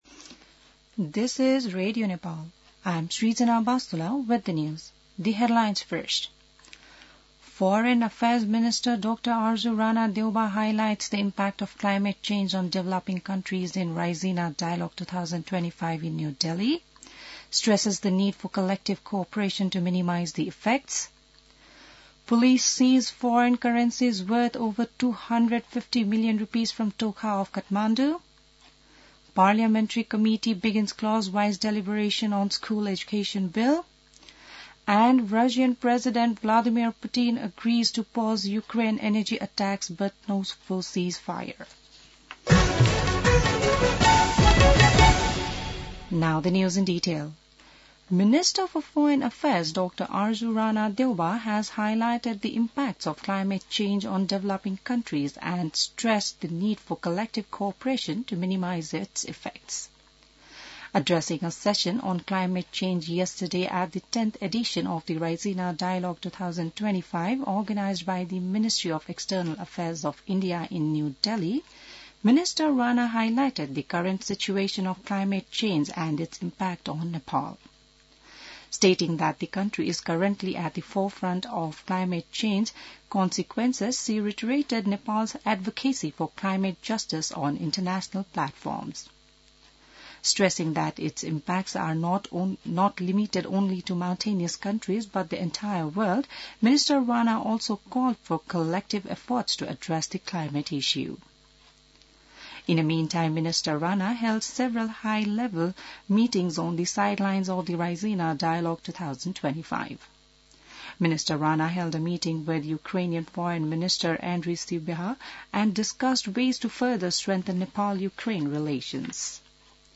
बिहान ८ बजेको अङ्ग्रेजी समाचार : ६ चैत , २०८१